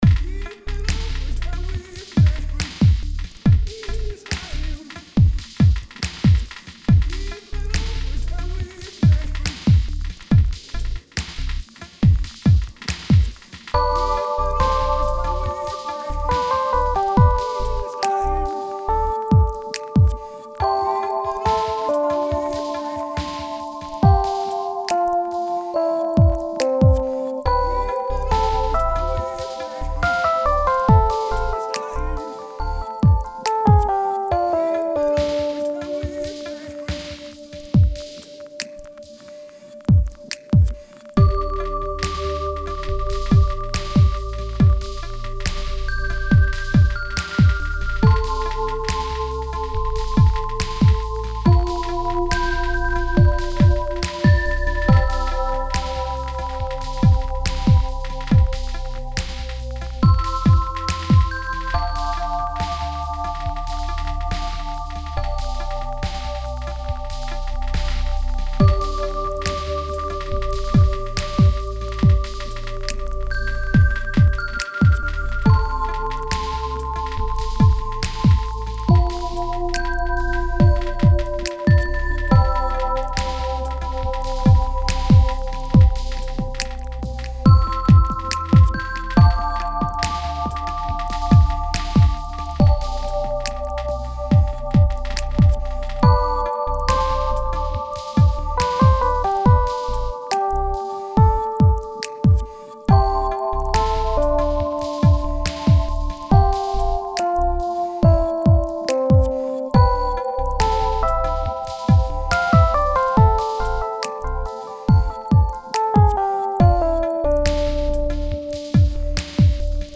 I rarely make slow and quiet songs.
Filed under: Instrumental | Comments (4)